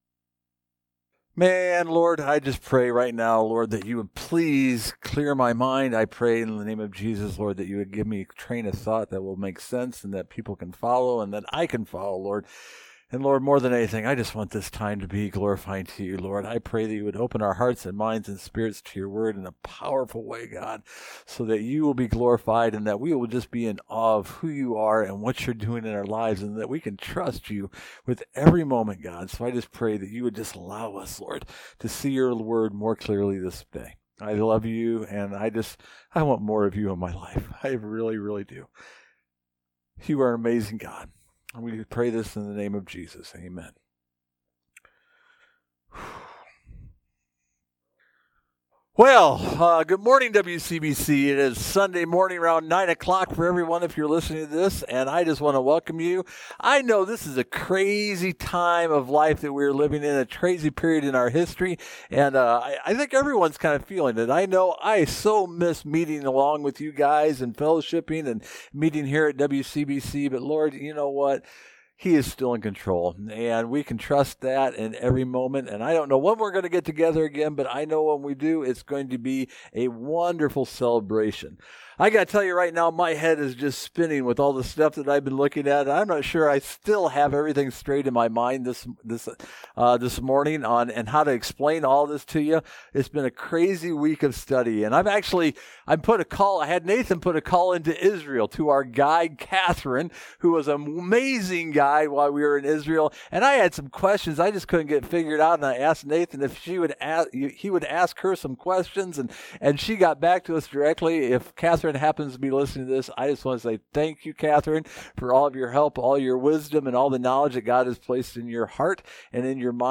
Service Type: Online Message